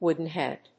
アクセント・音節wóoden・hèad